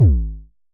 Festival Kicks 09 - G#1.wav